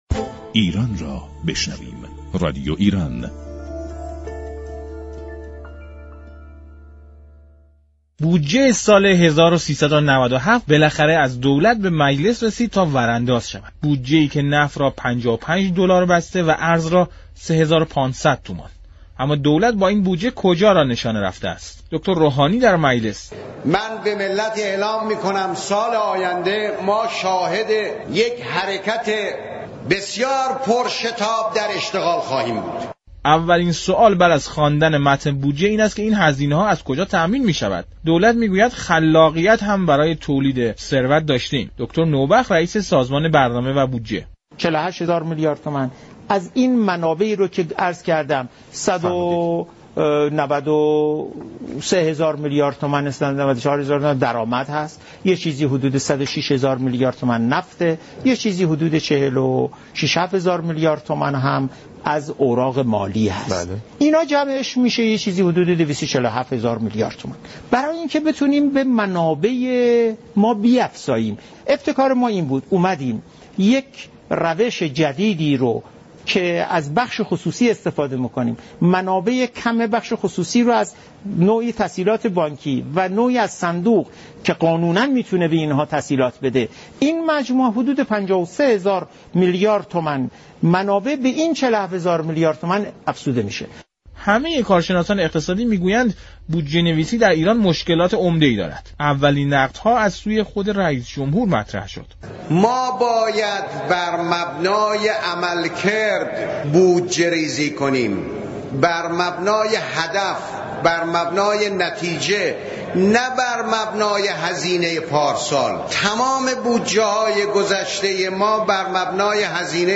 مستندی درباره ویژگی های بودجه سال 97 از جمله شفاف سازی و عدم تمركز